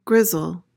PRONUNCIATION: (GRIZ-uhl) MEANING: verb tr.:To make gray. verb intr.:1.
grizzle.mp3